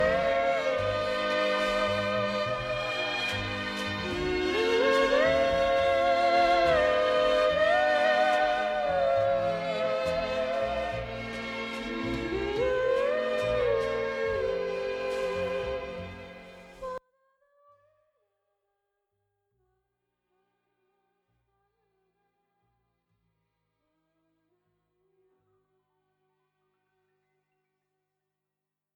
Описание: Вокализ.